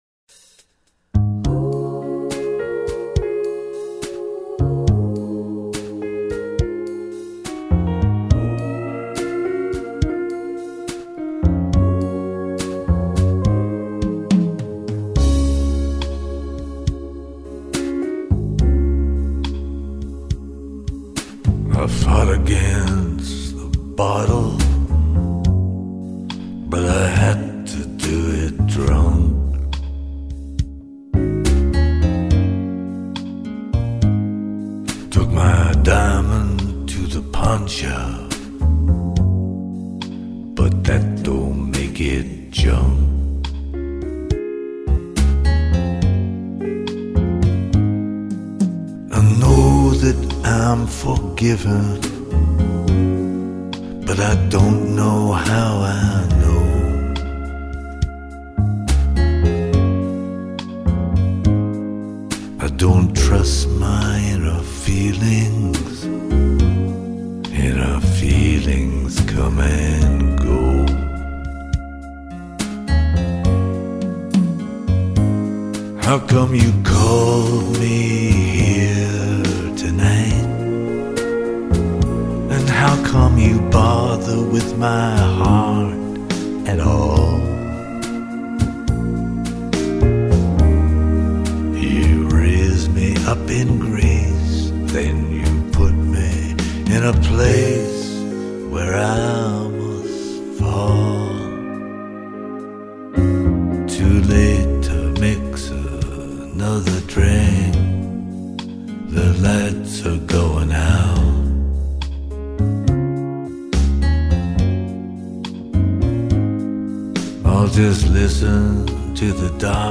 呀，这嗓子真是磁噢
没话说了，这种嗓子就是我最喜欢的一种
最喜欢这种声音